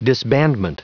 Prononciation du mot disbandment en anglais (fichier audio)
Prononciation du mot : disbandment